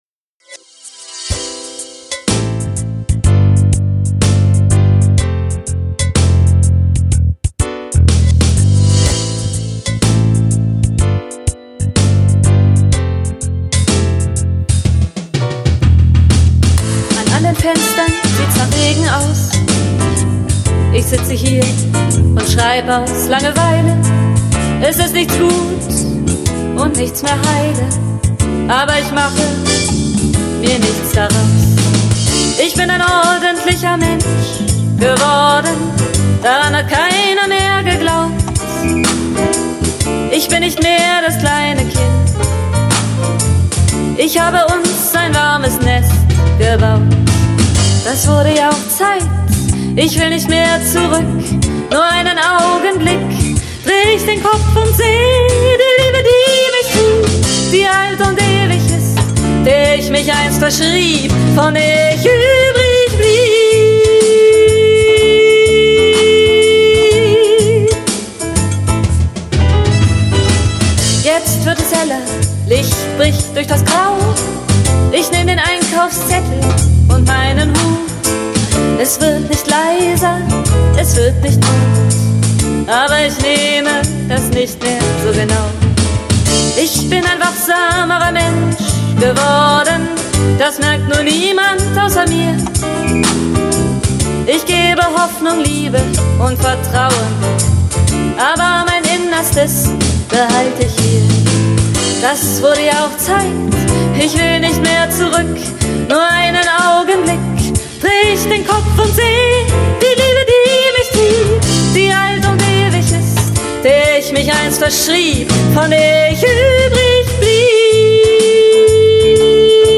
Combo-Version